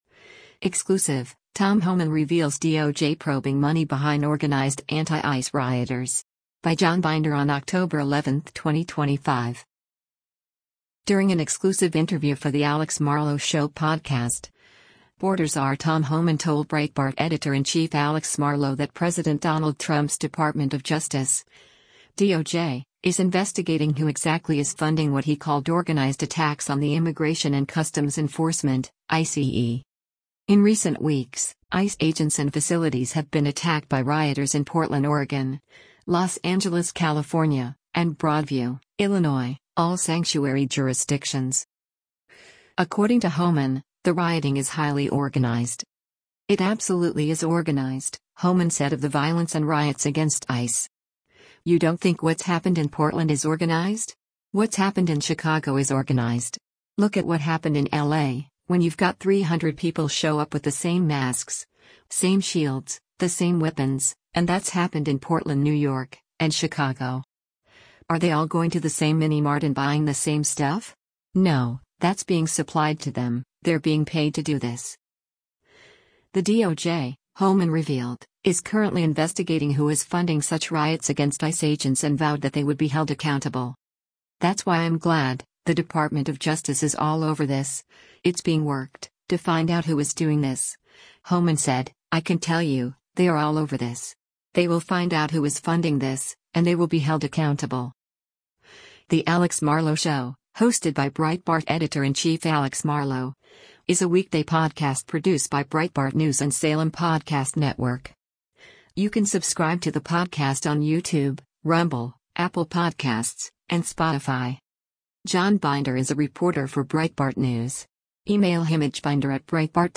During an exclusive interview for The Alex Marlow Show podcast, Border Czar Tom Homan told Breitbart Editor-in-Chief Alex Marlow that President Donald Trump’s Department of Justice (DOJ) is investigating who exactly is funding what he called “organized” attacks on the Immigration and Customs Enforcement (ICE).